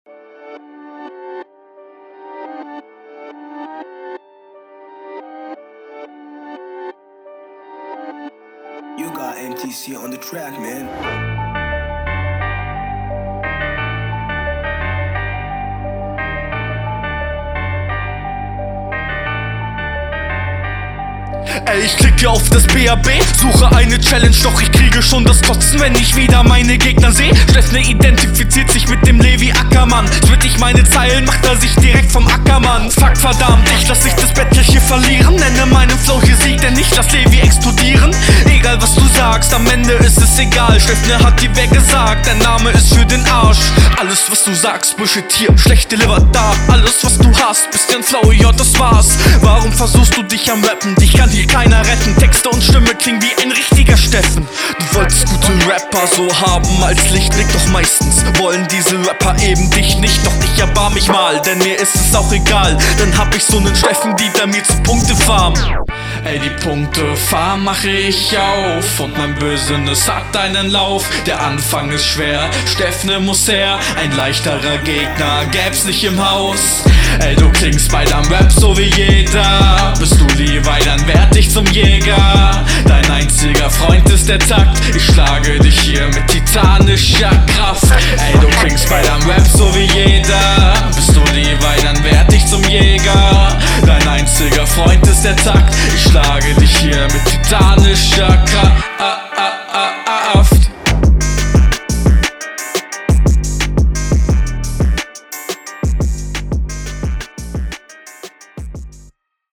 Battle Runden
Flowlich in dem Battle deine Stärkste definitiv.